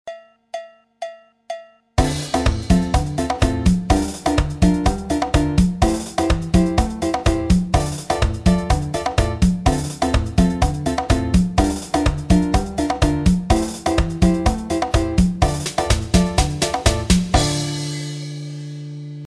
Figure guitare candomble 1 avec section rythmique.